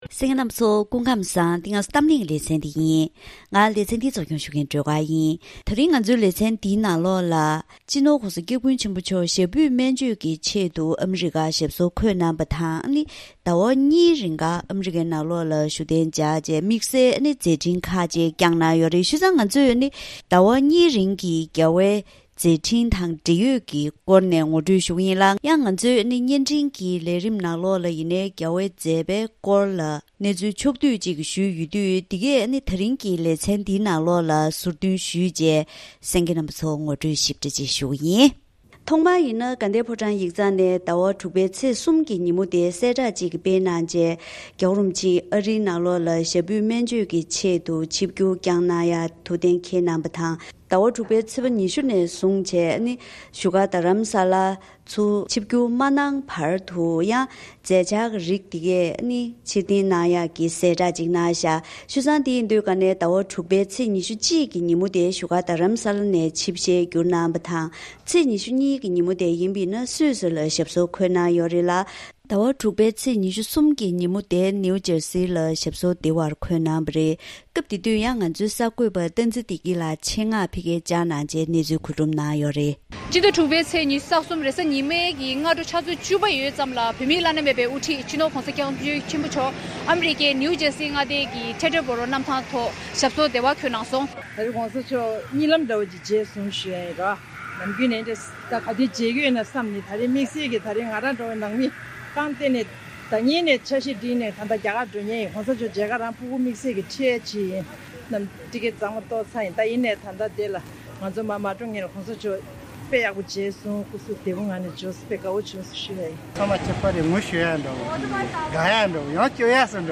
ད་རིང་གི་གཏམ་གླེང་ལེ་ཚན་ནང་སྤྱི་ནོར་༧གོང་ས་༧སྐྱབས་མགོན་ཆེན་པོ་མཆོག་ཞབས་པུས་སྨན་བཅོས་ཆེད་ཨ་རིའི་ནང་ཟླ་བ་གཉིས་རིང་བཞུགས་ཞག་མཛད་ཡོད་ཅིང་། དུས་ཚོད་དེའི་རིང་༧གོང་ས་མཆོག་གི་ཞབས་པུས་སྨན་བཅོས་དང་དམིགས་བསལ་མཛད་འཕྲིན་བསྐྱངས་པ་ཁག་ཨེ་ཤེ་ཡ་རང་དབང་རླུང་འཕྲིན་ཁང་ནས་གནས་ཚུལ་ཆ་ཚང་པོ་ཞིག་ཕྱོགས་སྒྲིག་ཞུས་པ་ཞིག་གསན་རོགས་གནང་།